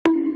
donk.mp3